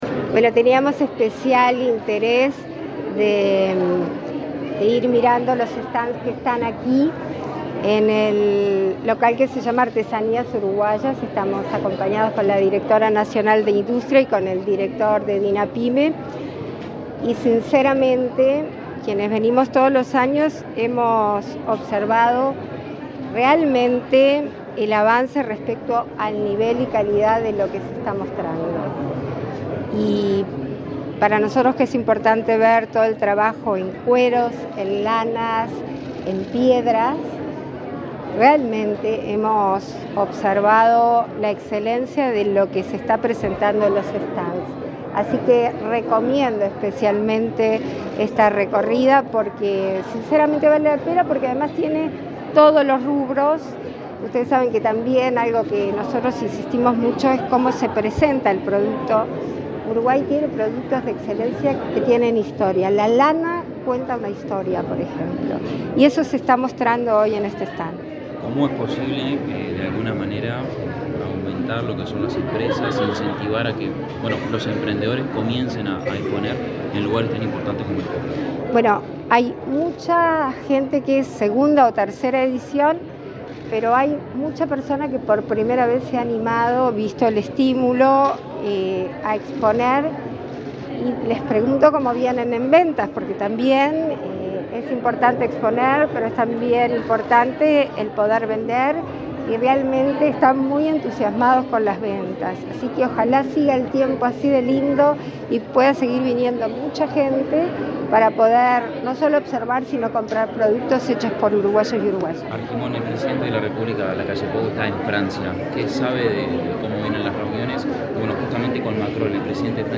Declaraciones de la presidenta en ejercicio, Beatriz Argimón, a la prensa
La presidenta de la República en ejercicio, Beatriz Argimón, se expresó para medios informativos luego de visitar el stand del Ministerio de Industria